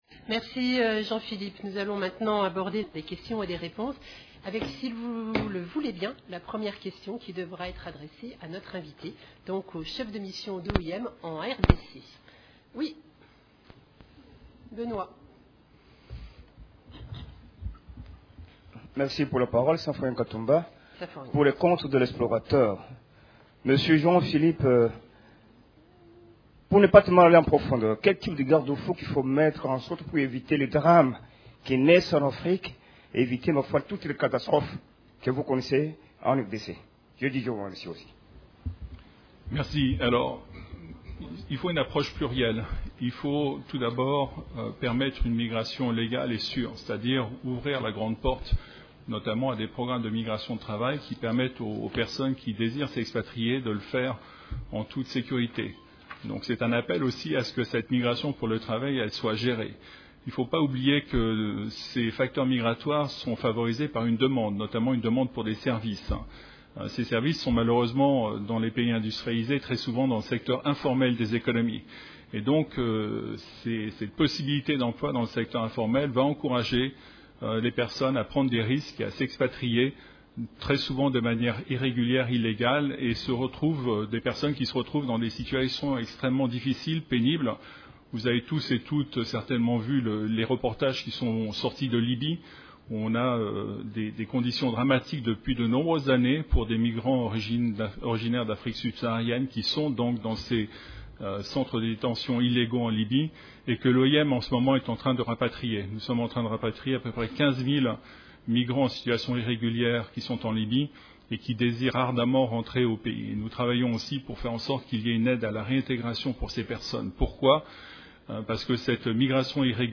Conférence de presse du mercredi 20 décembre 2017
La visite du secrétaire général adjoint de l'ONU chargé des opérations de maintien de la paix, Jean-Pierre Lacroix, la Journée internationale des migrants, la situation sécuritaire et humanitaire caractérisée par le développement de l'épidémie du choléra en 2017 ont été au centre de la conférence de presse hebdomadaire des Nations unies (en vidéo) à Kinshasa et à Goma.
Ecoutez la première partie de cette conférence de presse: /sites/default/files/2017-12/conf_hebdo-1ere_partie-web.mp3 Ecoutez également la deuxième de la conférence de presse consacrée aux questions et réponses: /sites/default/files/2017-12/conf_hebdo-2e_partie-web.mp3